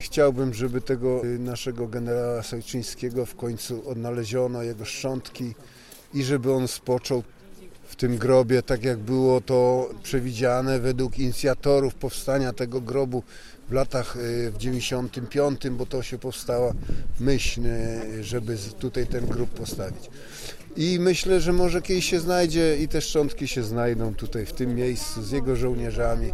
76. rocznicę śmierci generała Sojczyńskiego "Warszyca" upamiętniono w Radomsku.
Obecny na uroczystości, wicemarszałek województwa Zbigniew Ziemba, mówił, że takie wydarzenia, jak dziś, budują i utrwalają tożsamość regionu, opartą o wartości patriotyczne.